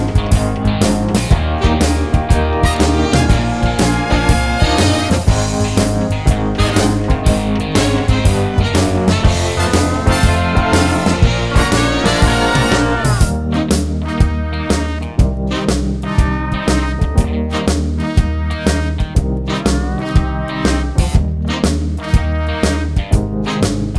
no Backing Vocals Jazz / Swing 3:50 Buy £1.50